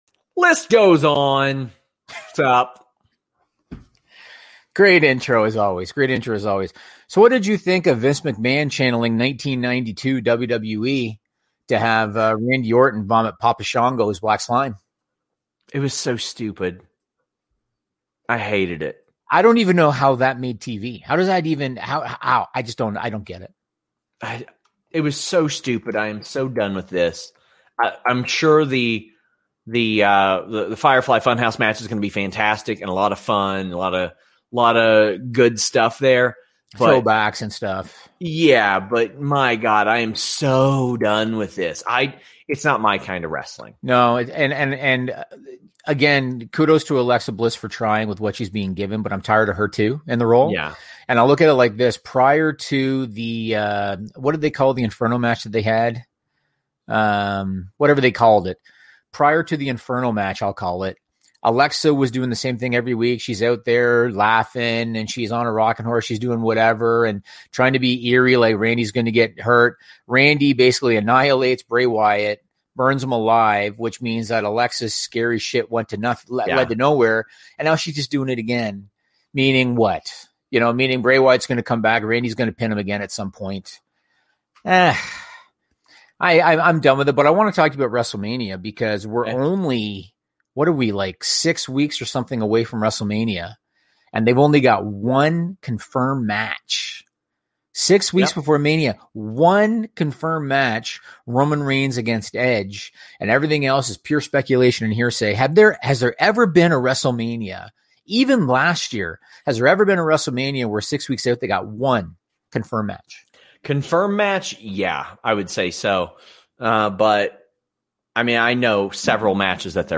The List post-show, LIVE